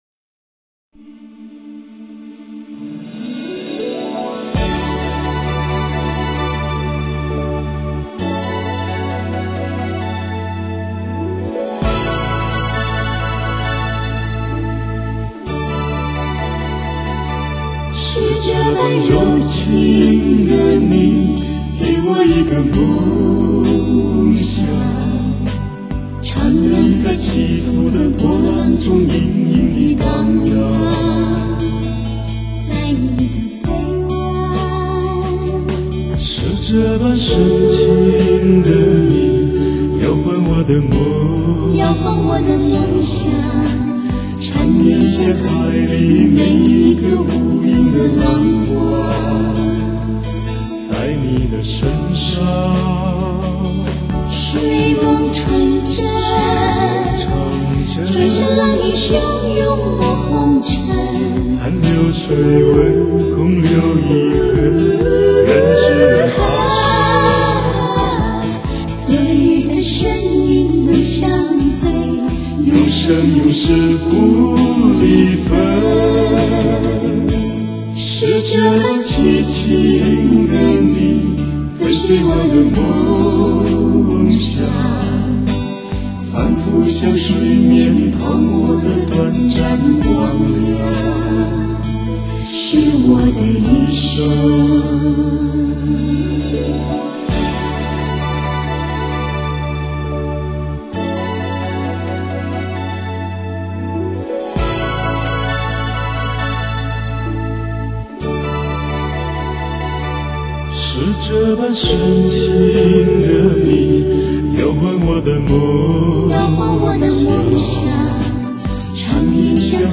风格独特、韵味浓郁、细腻典雅的经典金曲荟萃发烧专辑。